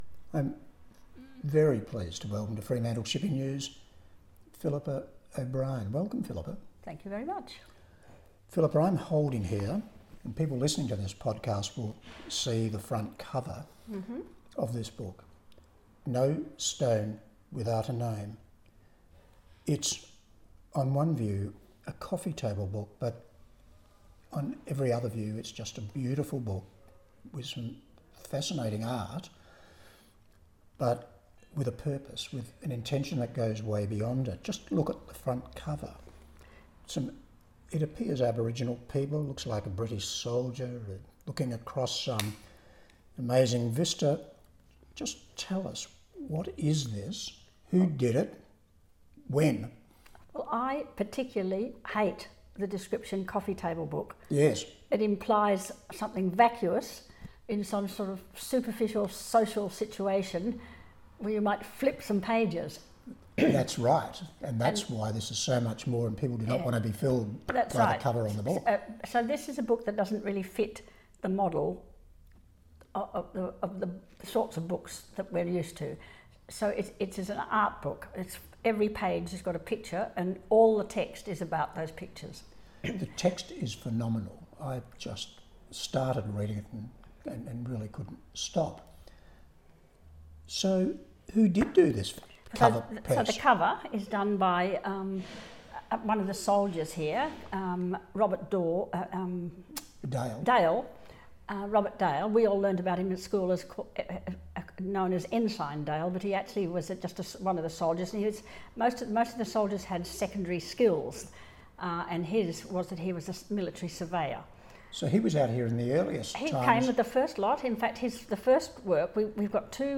No Stone Without A Name – Interview